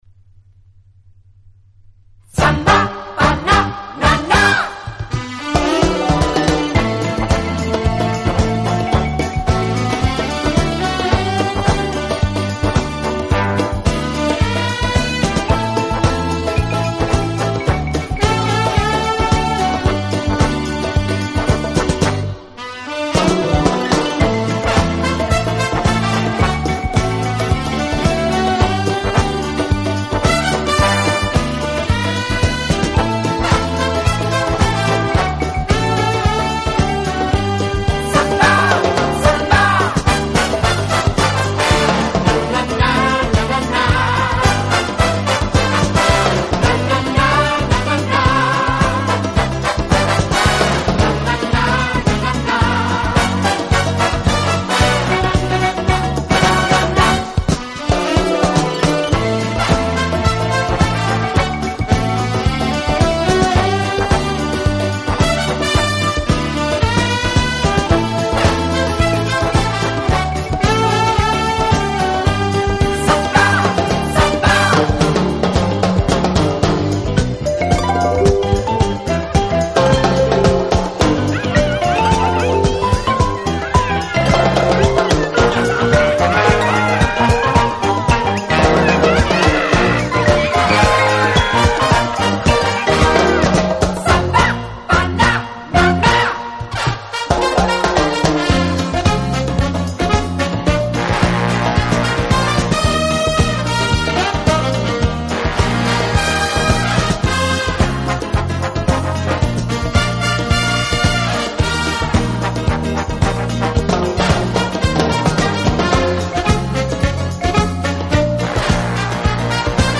【轻音乐】
方式，尤其是人声唱颂的背景部分，似乎是屡试不爽的良药。
有动感，更有层次感；既有激情，更有浪漫。